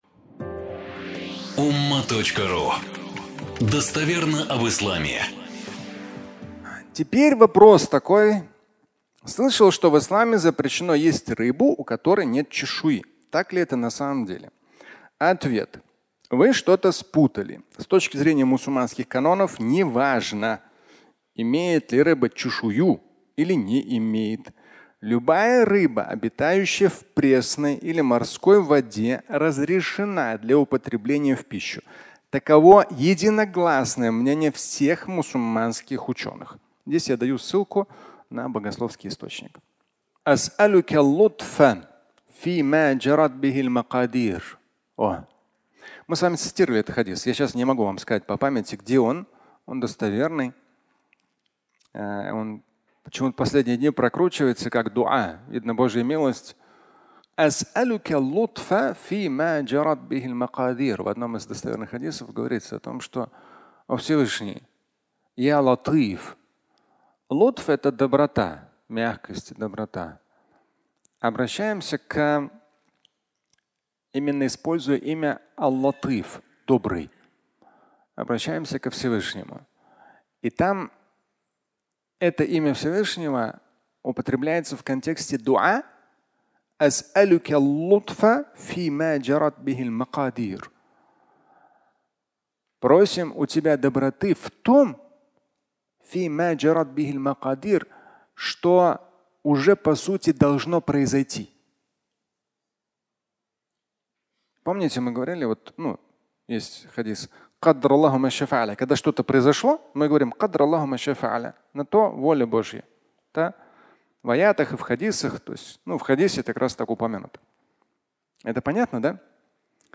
Фрагмент пятничной лекции